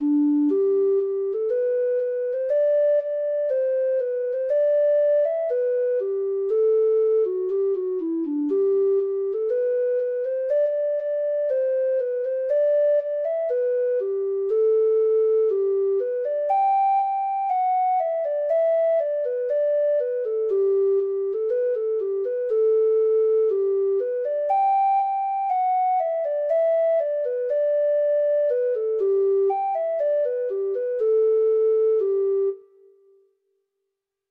Treble Clef Instrument version